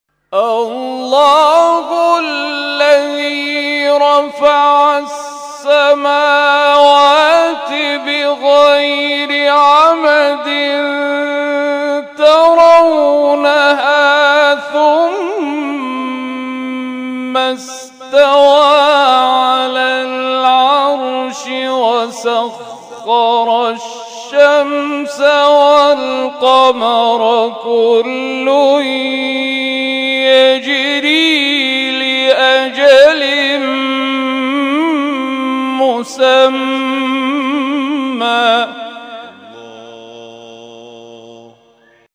گروه جلسات و محافل ــ محفل انس با قرآن این هفته آستان عبدالعظیم الحسنی(ع) با تلاوت قاریان ممتاز و بین‌المللی کشورمان برگزار شد.